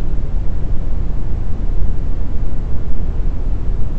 A320_aircond.wav